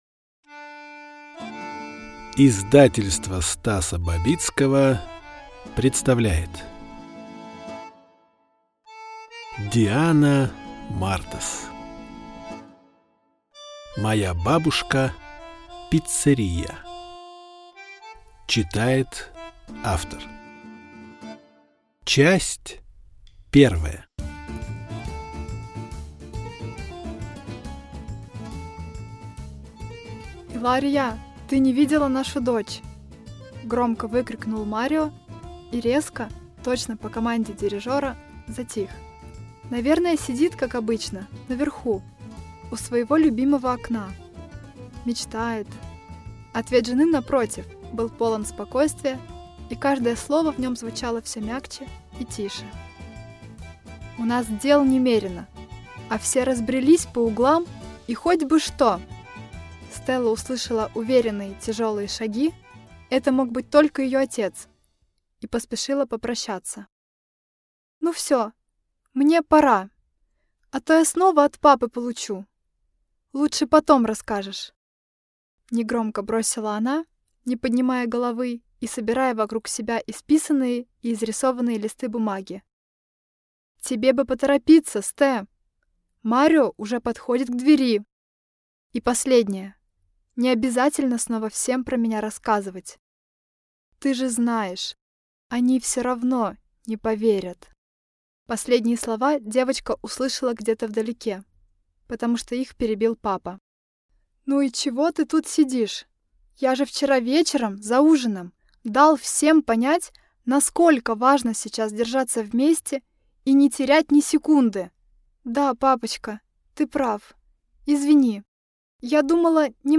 Аудиокнига Моя бабушка – пиццерия | Библиотека аудиокниг